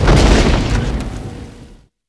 explo_2part1.wav